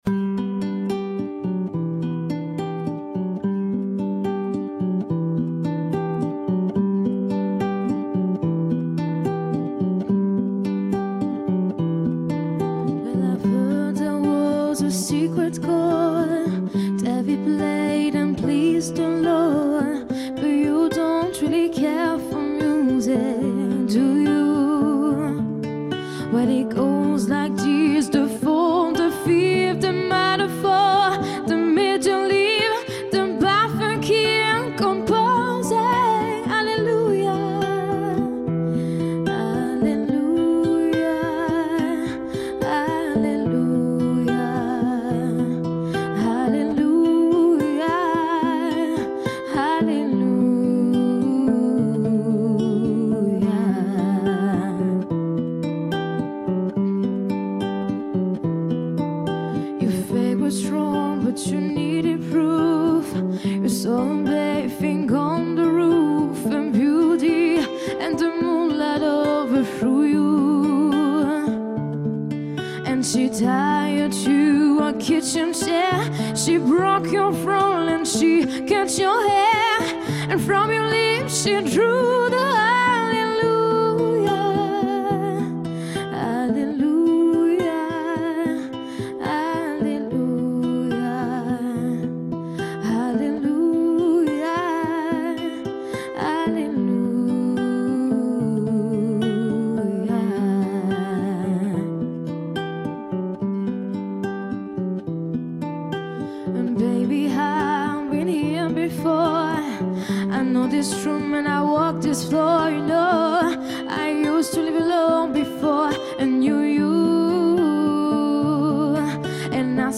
MP3 / Korg / Trompette / Saxo